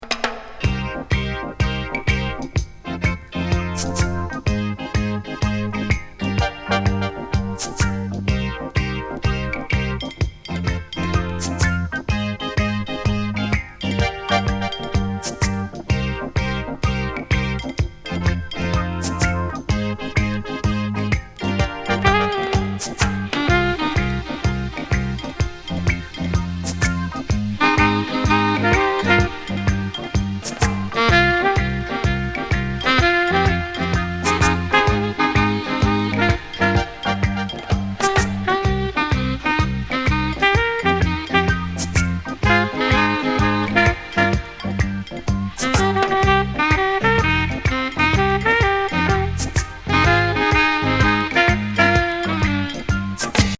イタリアのミスターエロジャケット、ムードミュージック・サックス奏者79年作。